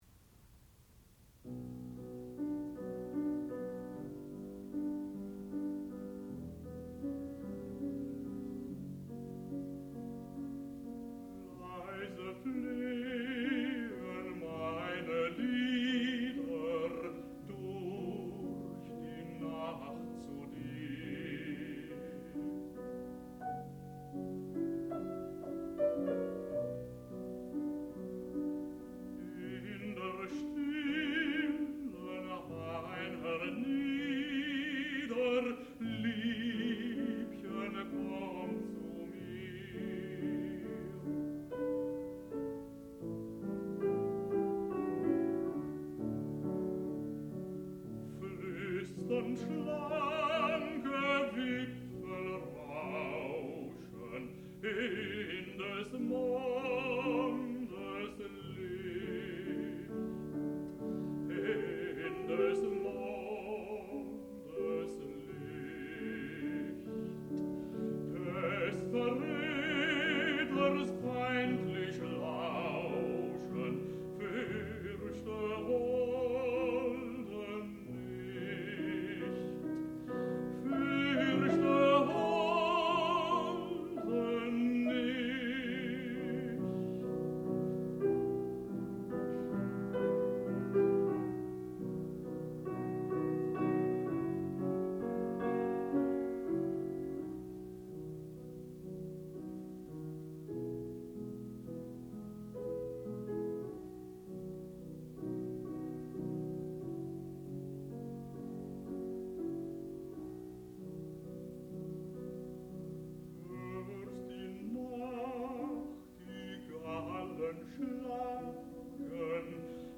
sound recording-musical
classical music
Arve Tellefsen, violin and Eva Knardahl, piano (performer).